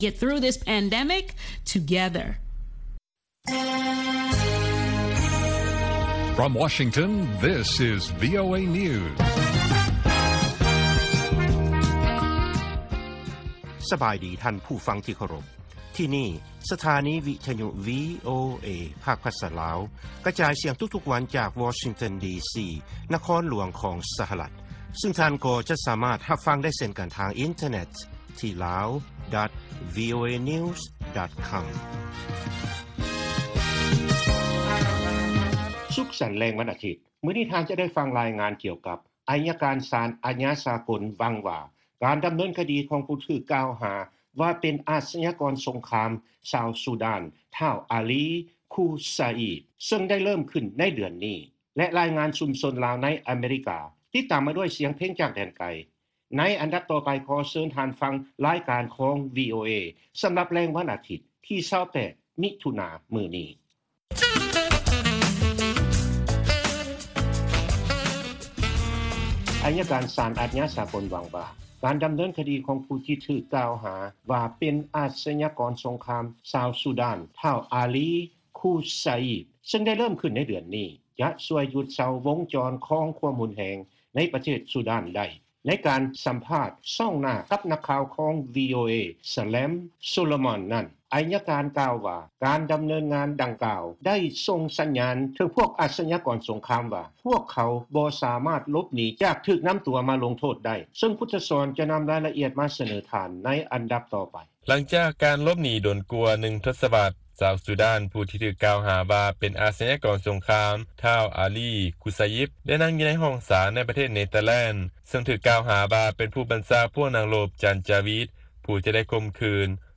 ລາຍການກະຈາຍສຽງຂອງວີໂອເອ ລາວ
ວີໂອເອພາກພາສາລາວ ກະຈາຍສຽງທຸກໆວັນ.